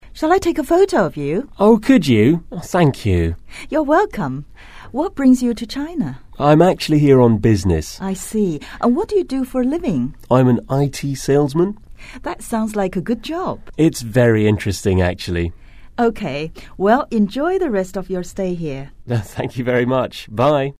english_38_dialogue_2.mp3